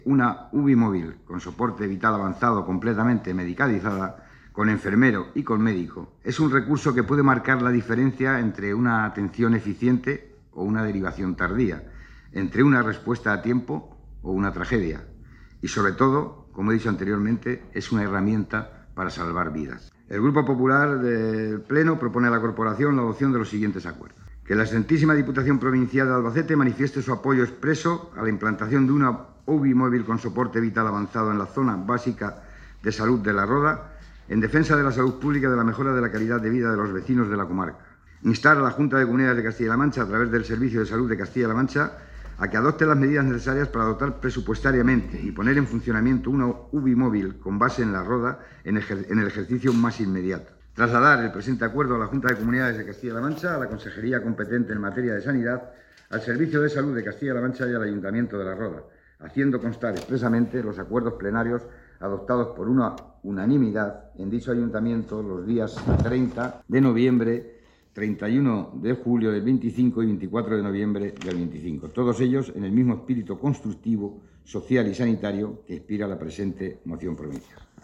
cortedesonidojuanjosegrandemoinuv.mp3